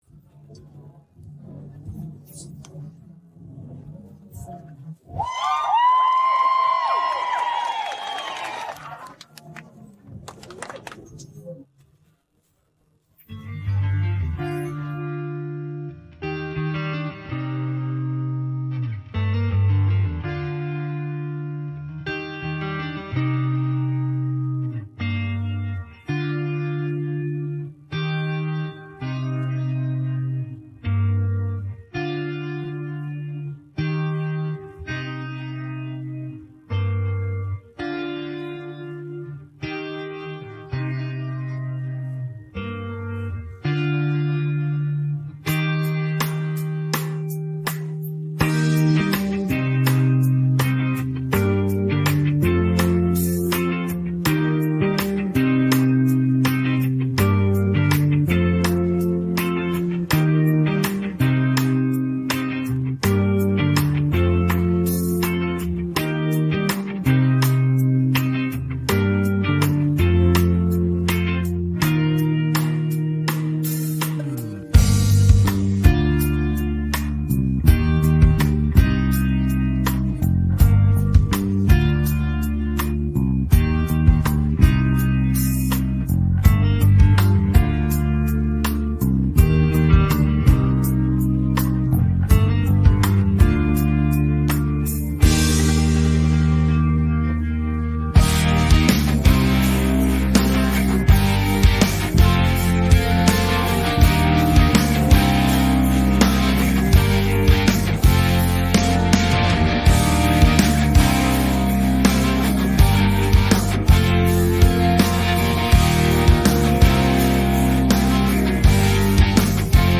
pop rock караоке 16